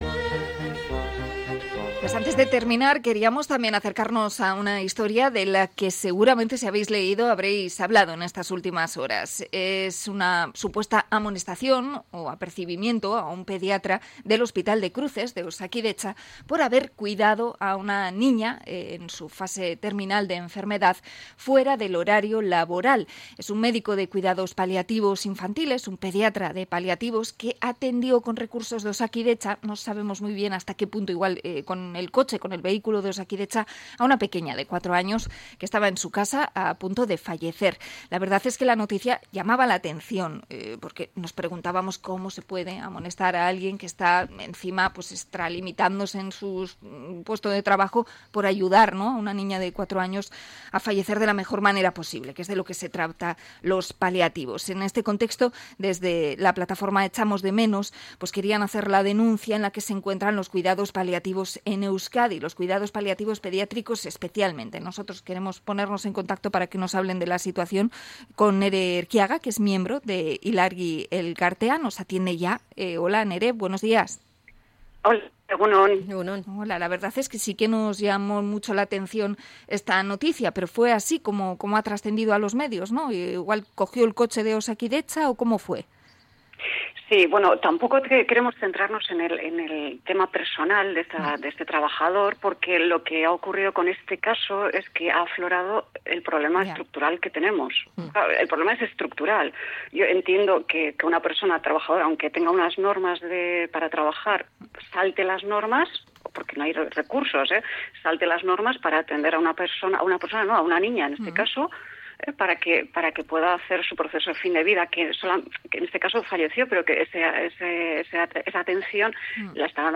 Entrevista a la plataforma Echamos de menos por el caso del pediatra que atendió a una niña fuera de servicio